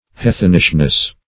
Meaning of heathenishness. heathenishness synonyms, pronunciation, spelling and more from Free Dictionary.
Search Result for " heathenishness" : The Collaborative International Dictionary of English v.0.48: Heathenishness \Hea"then*ish*ness\, n. The state or quality of being heathenish.